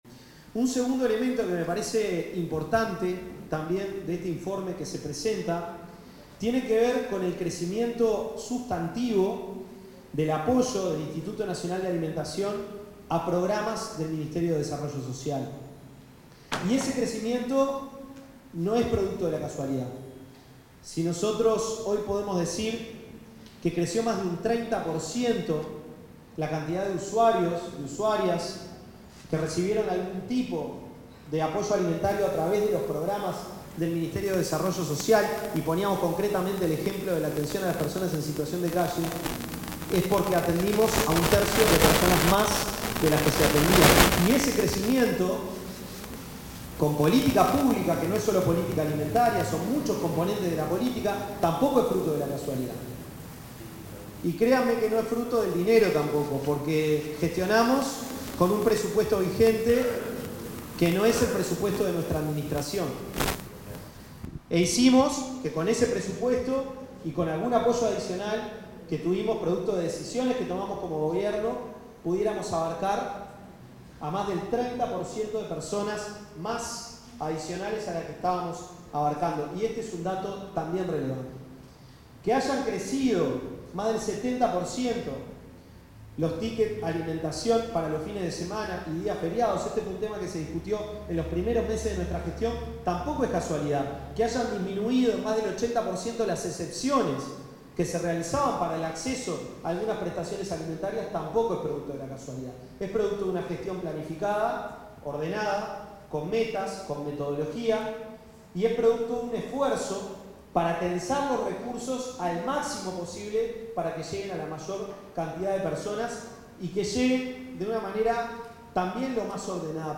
Palabras del ministro de Desarrollo Social, Gonzalo Civila
El titular del Ministerio de Desarrollo Social (Mides), Gonzalo Civila, hizo uso de la palabra en la presentación de los avances de gestión, las